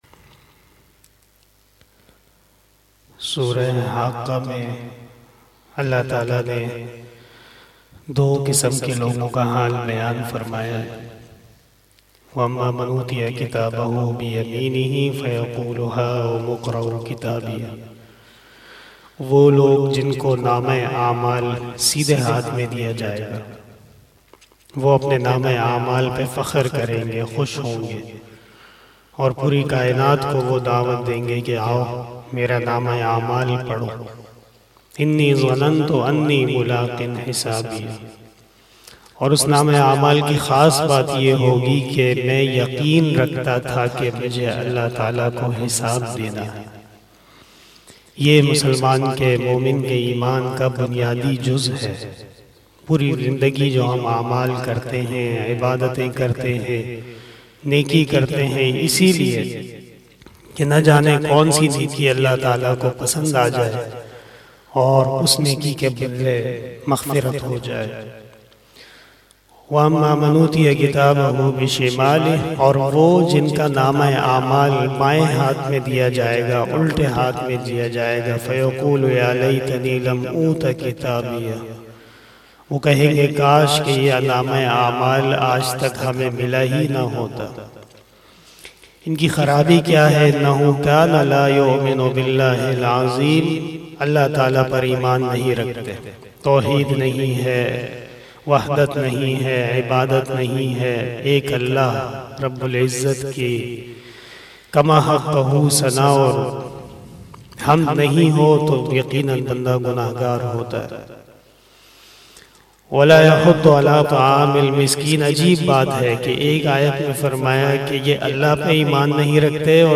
016 After Fajr Namaz Bayan 2 March 2022 ( 28 Rajab ul Murajjab 1443HJ) Wednesday